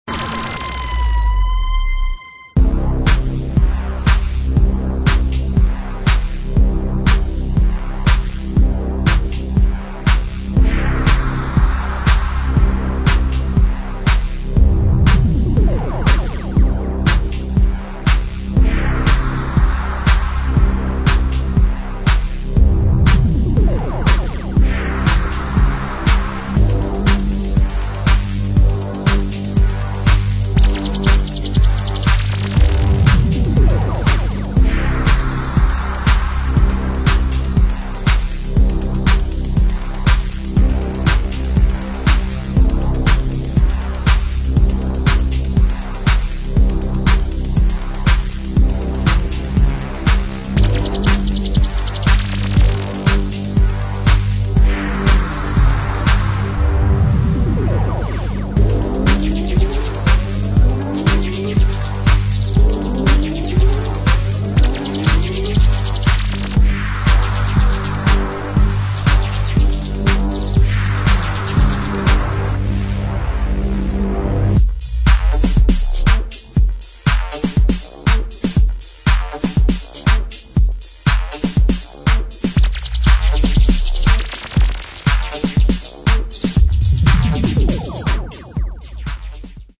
[ DEEP HOUSE / DEEP MINIMAL / COSMIC DISCO ]